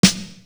Bang Bang Snare.wav